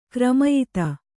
♪ kramayita